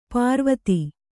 ♪ pārvati